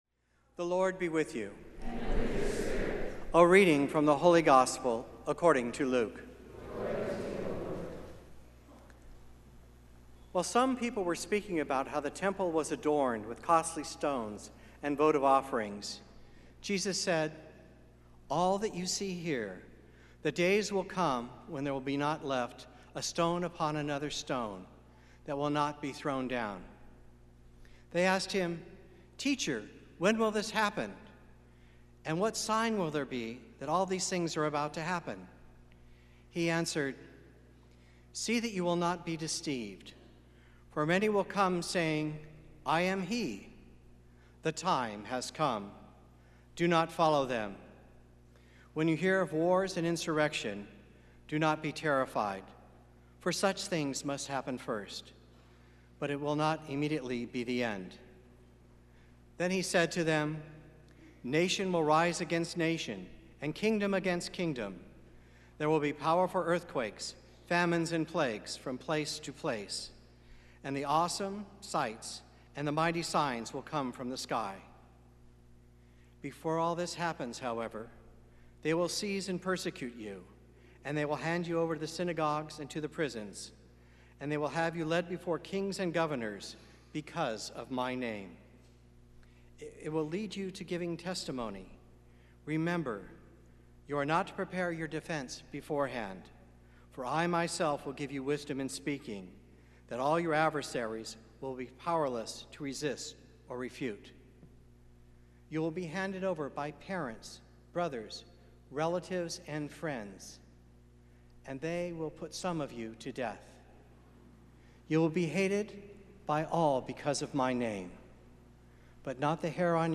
Gospel and Homily Podcasts